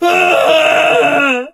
ash_die_vo_06.ogg